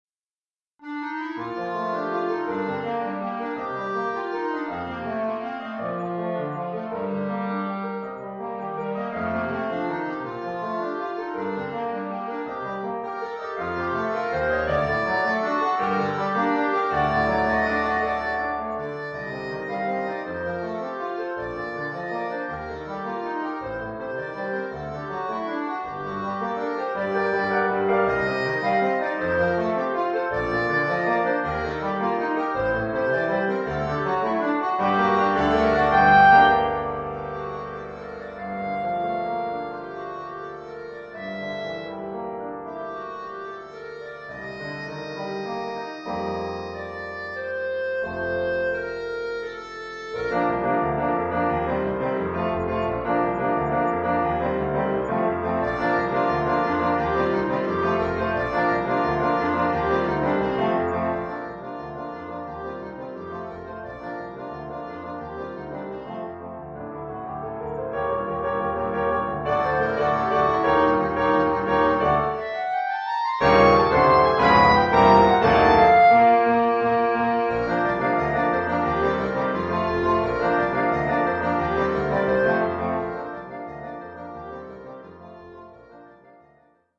Oeuvre pour clarinette et piano.